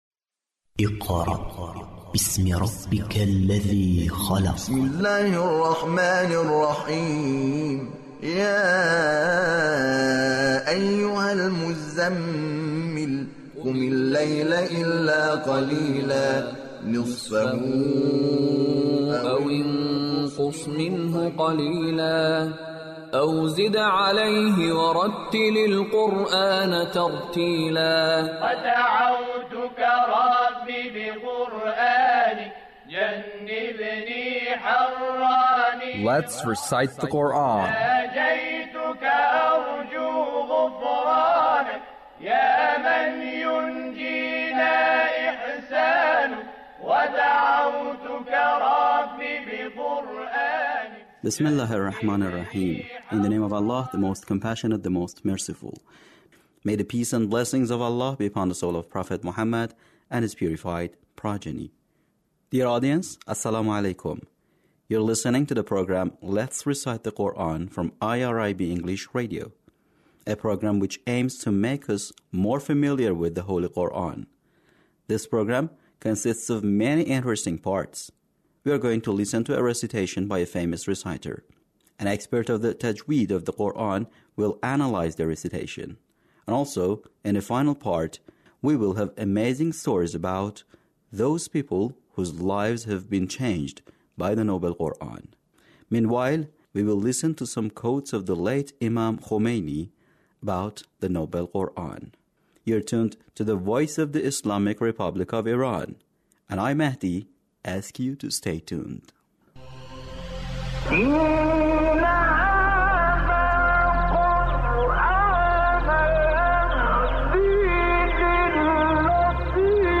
Recitation of surah al-Ahzab - Attractiveness of the Noble Quran